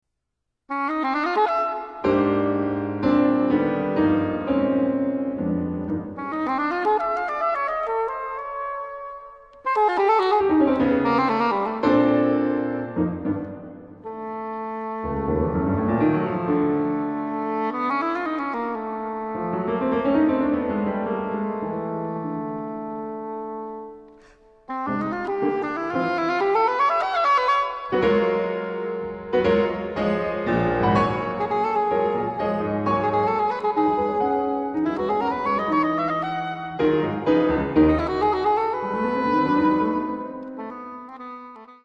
Five Pieces for English Horn and Piano